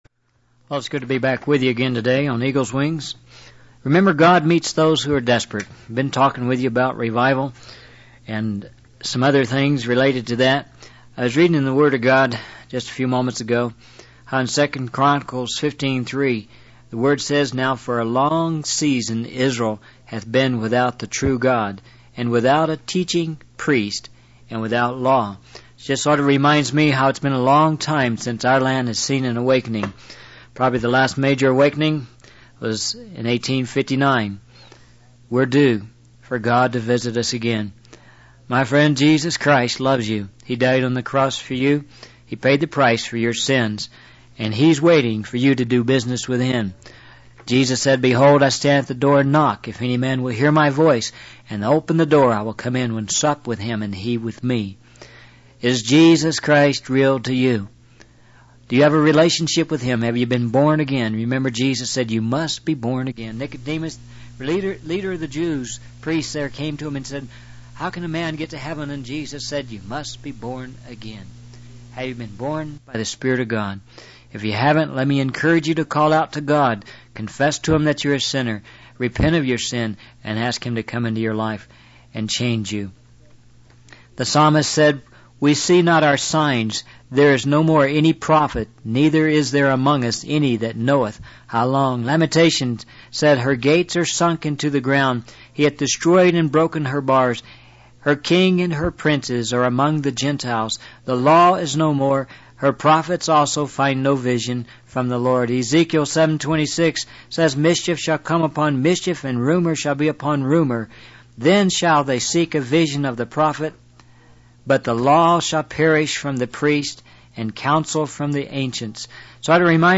In this sermon, the preacher emphasizes the importance of staying vigilant and warning others about the corruption of the word of God.